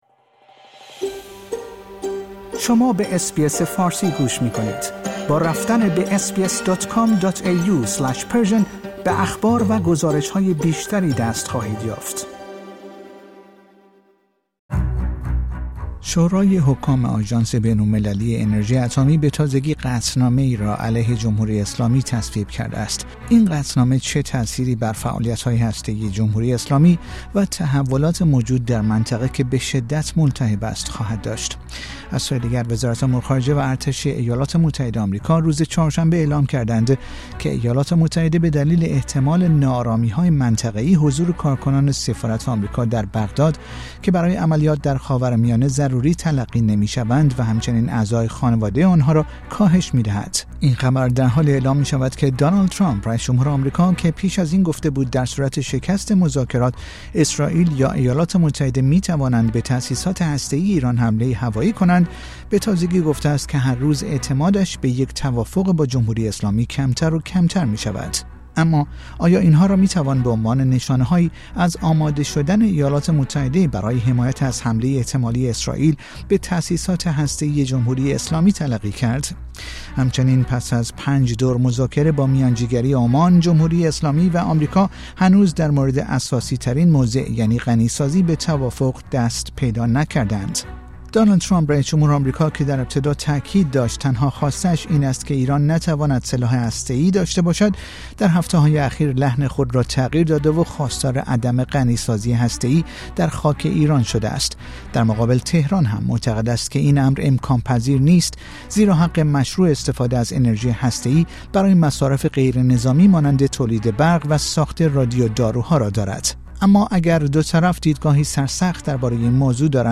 این گفتگو پیش از حمله اسرائیل به ایران انجام شده است